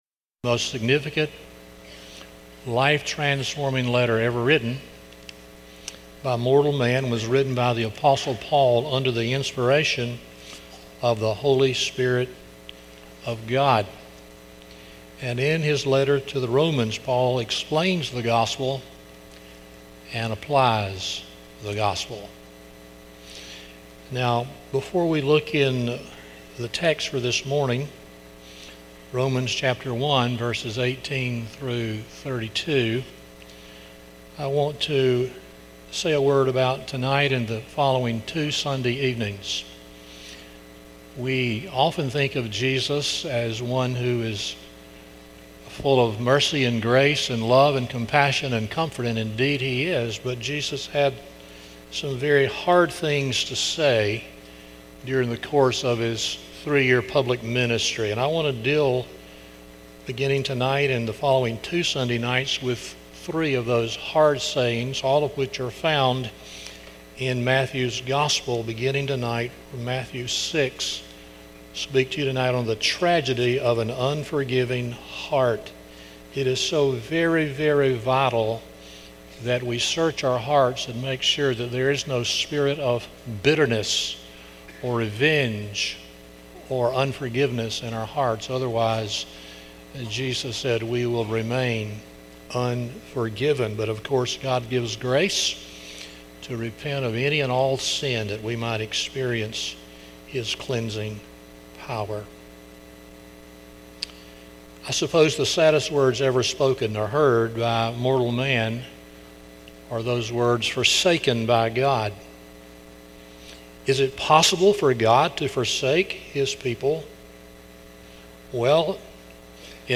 Romans 1:18-32 Service Type: Sunday Morning 1.